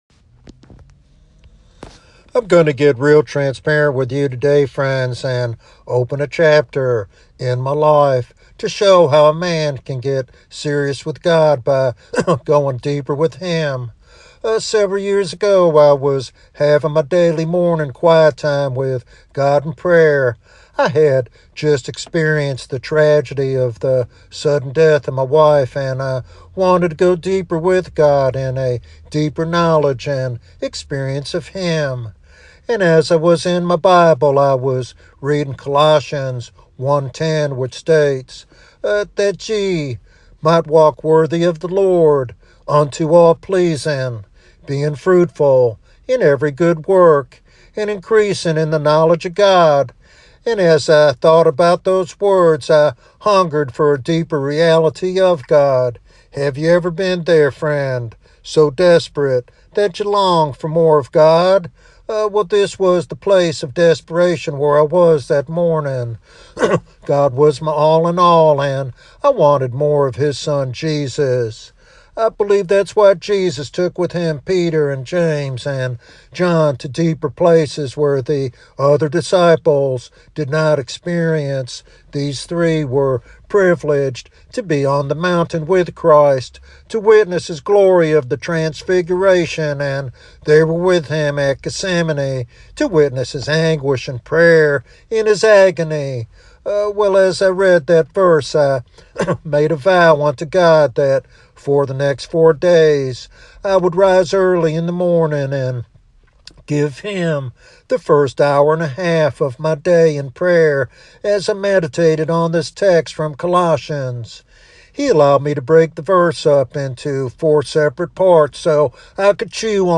This devotional message challenges believers to commit fully to God and receive His life-changing presence.